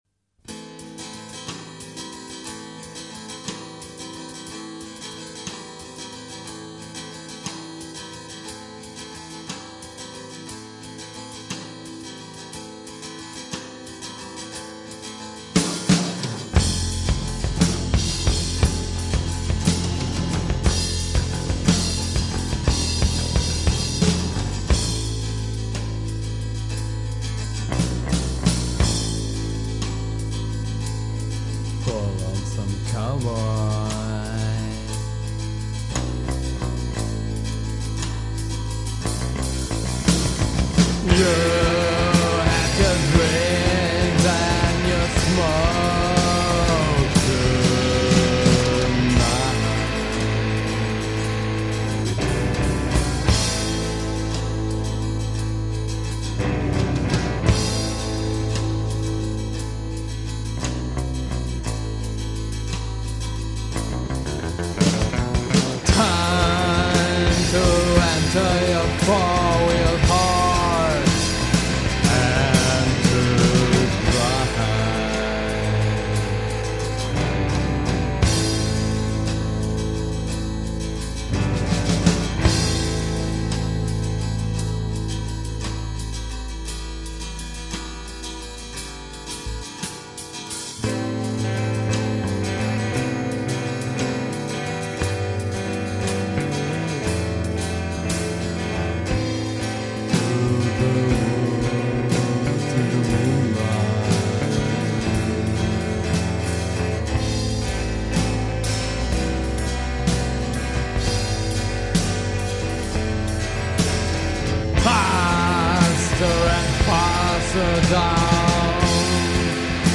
Gitarren Rock
voc, git, banjo, whistle, special noise effects
bass
drums